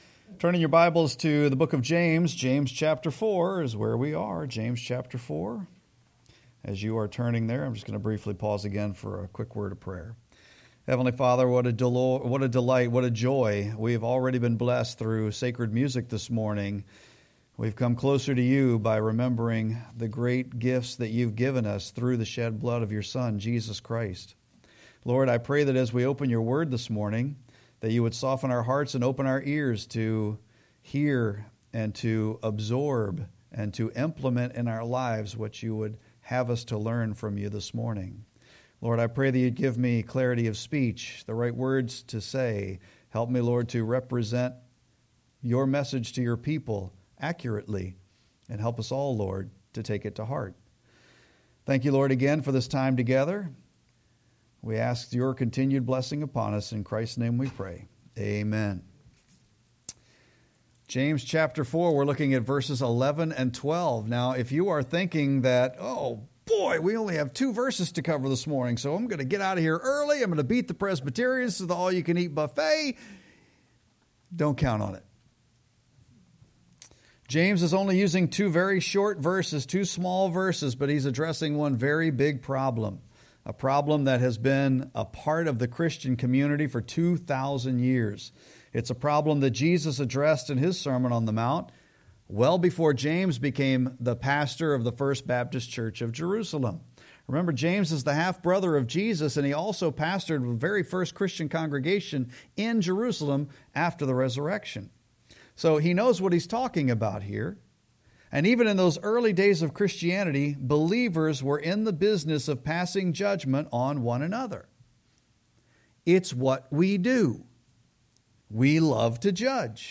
Serrmon-8-31-25.mp3